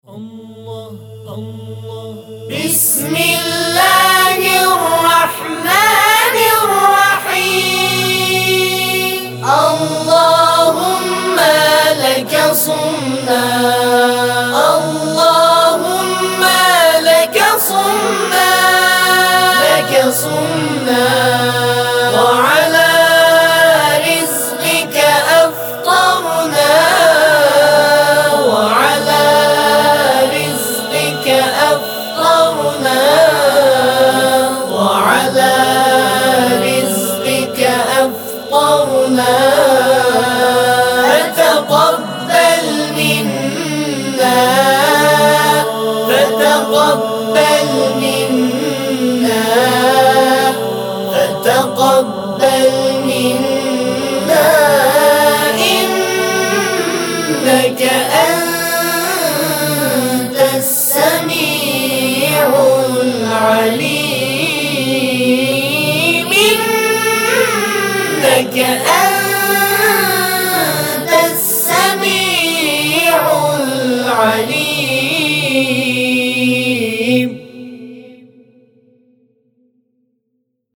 همخوانی دعای افطار | گروه تواشیح بین المللی تسنیم